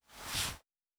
Throw in Normal.wav